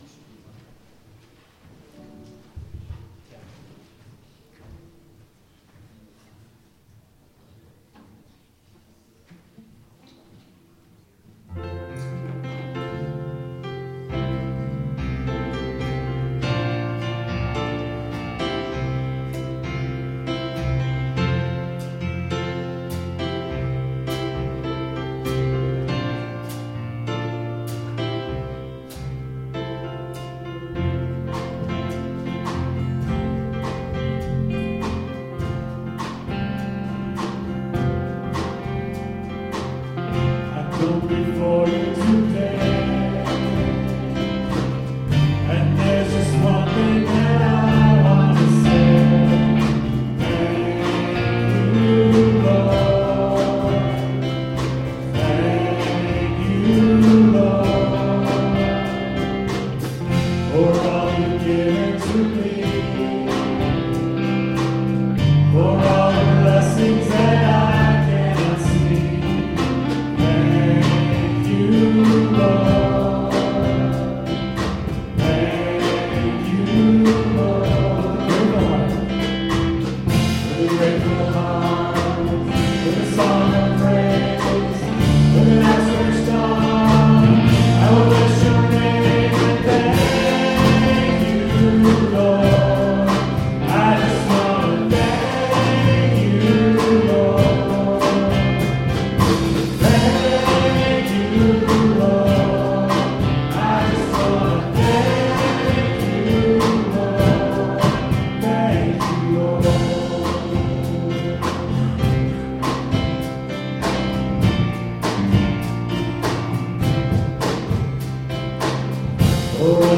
On Sunday, November 24th, we had a special Prayer & Thanksgiving service with our brothers and sisters from Glorious Triumphant Church.